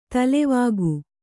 ♪ talevāgu